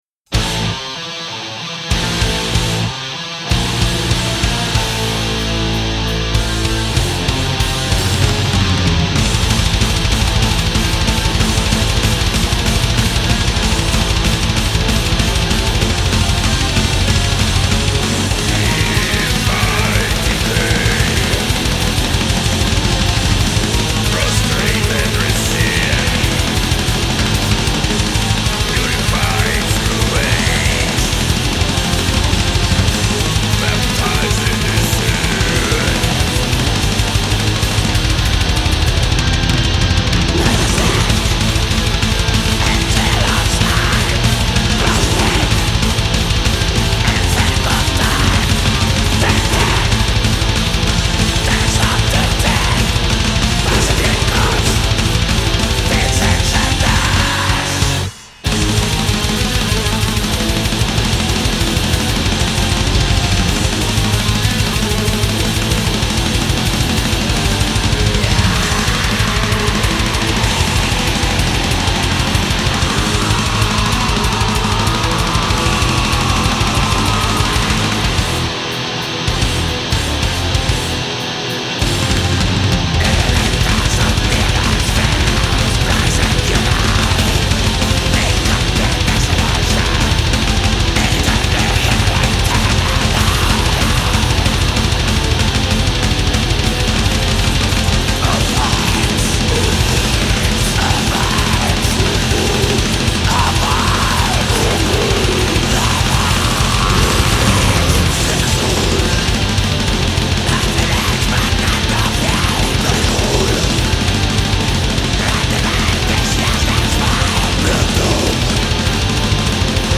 Genre Black metal